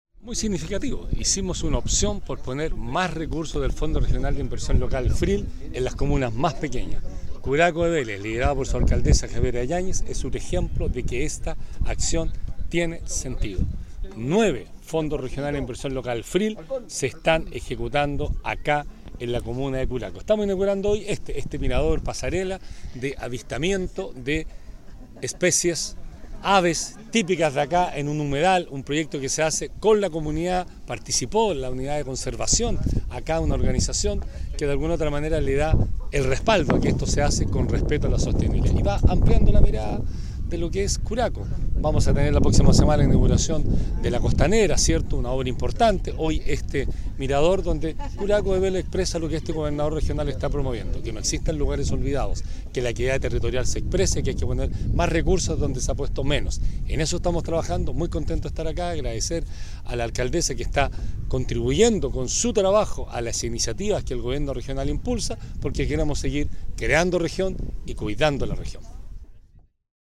En esta misma línea, el gobernador Vallespín subrayó la importancia que tiene para el Gobierno Regional aportar recursos a fin de que se ejecuten este tipo de obras que cuidan el medio ambiente:
gobernador-vallespin-pasarela-.mp3